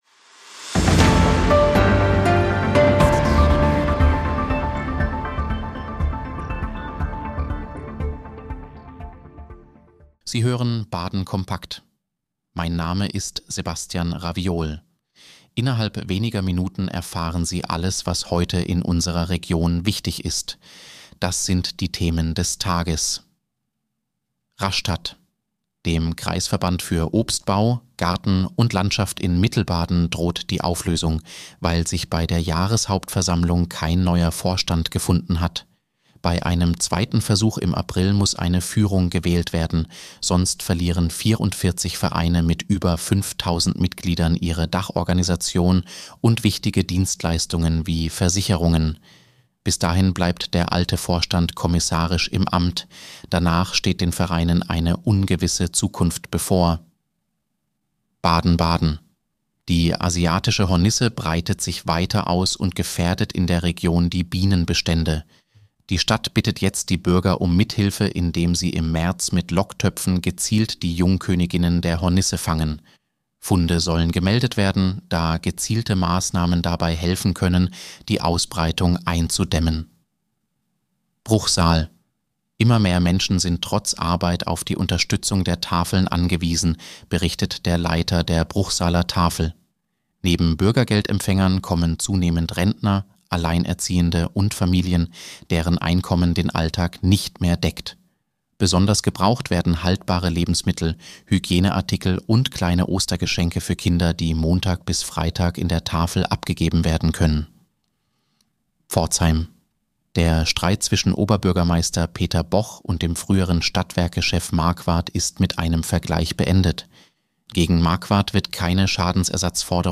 News für Baden